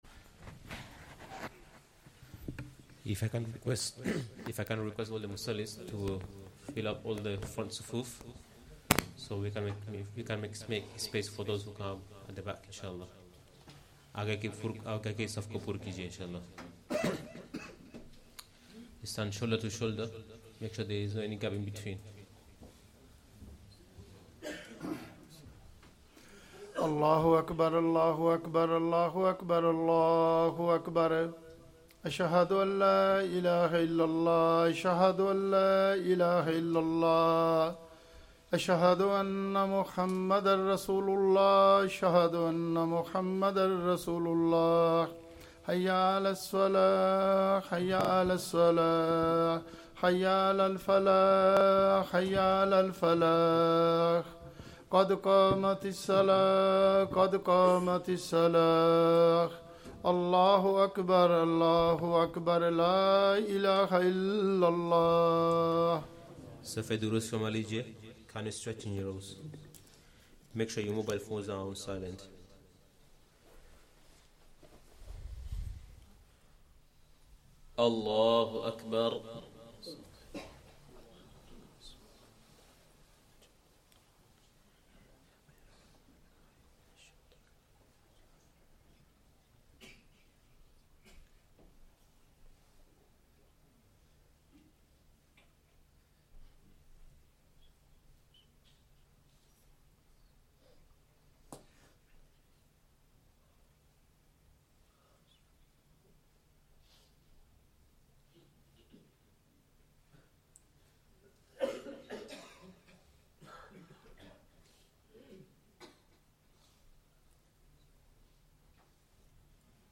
Asr salah + Bayaan
Masjid Adam, Ilford Recording Date